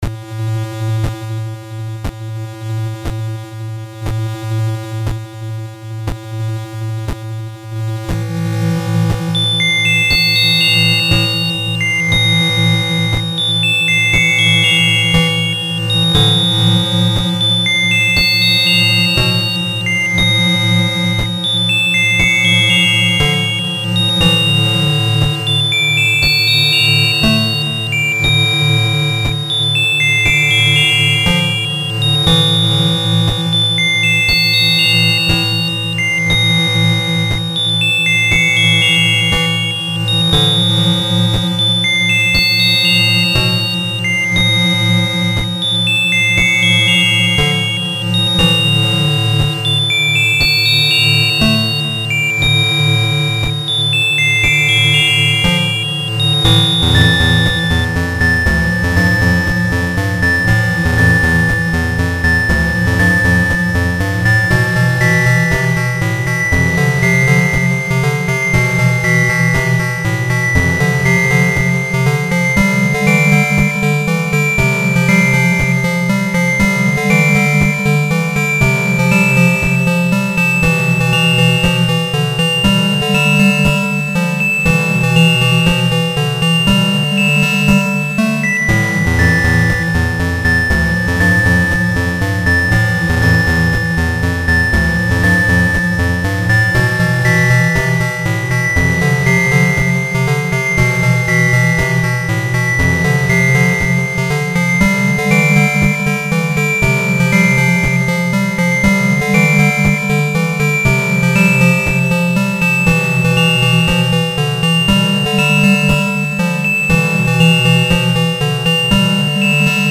So if you like music that sounds like it was made for a NES and don’t mind abrupt endings, you can check out some of the stuff I’ve been working on: